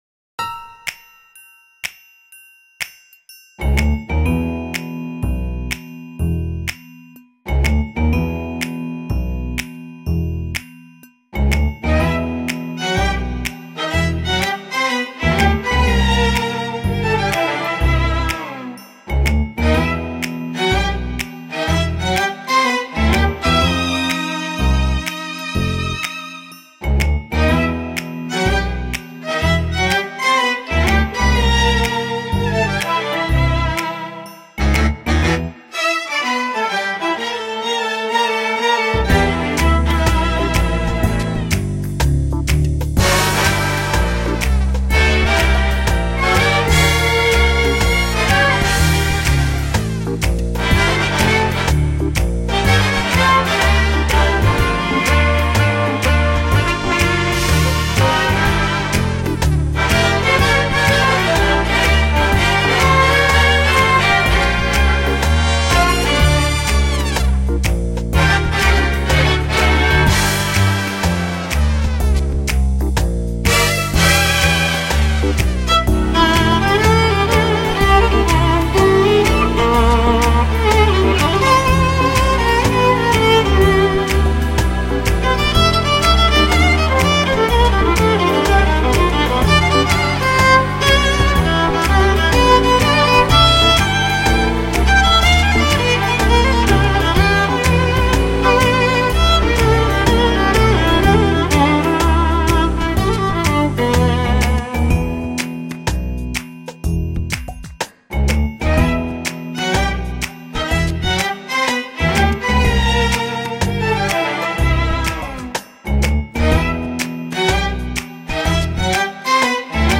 elektronické smyčcové trio